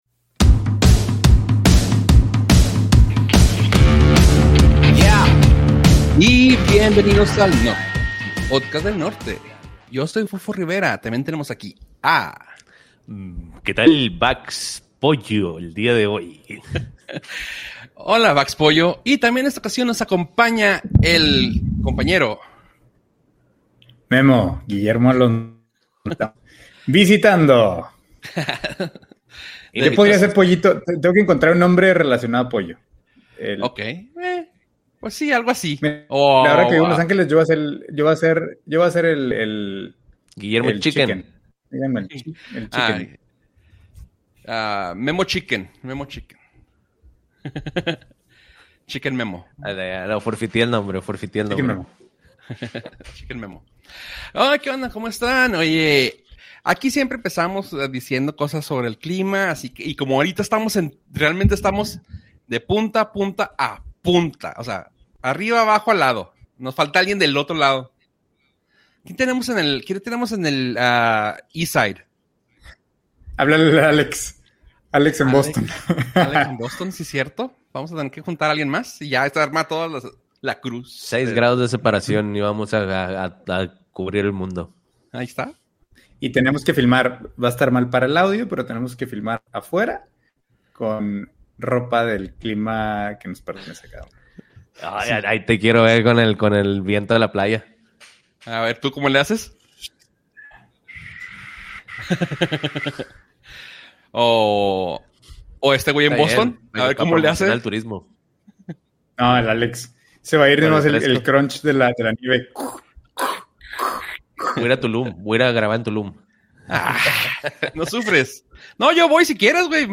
Nortcast Nortcast Un podcast de entretenimiento, tecnología y cultura pop.